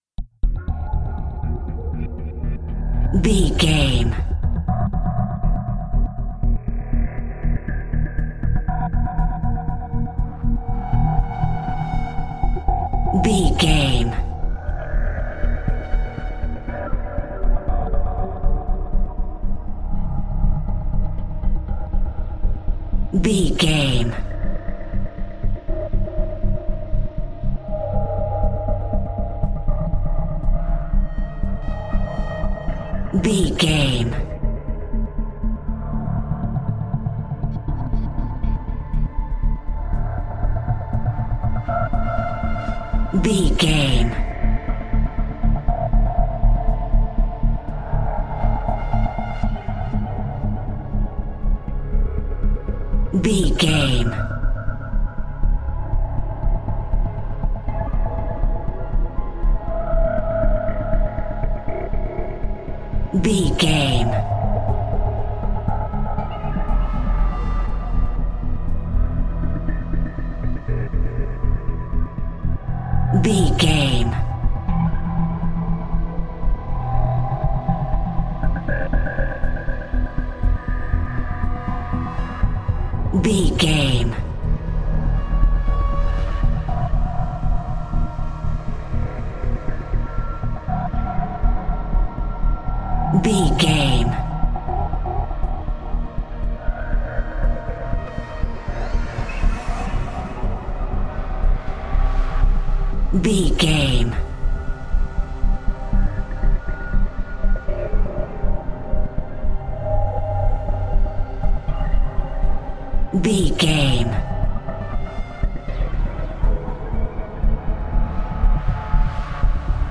Aeolian/Minor
E♭
Slow
synthesiser
ominous
dark
suspense
haunting
tense
creepy